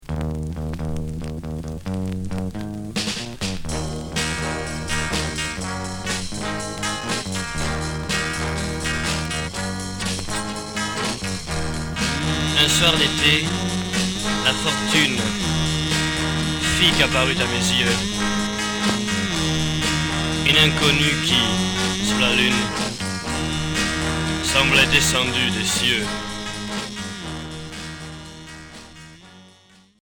Garage low-fi Test pressing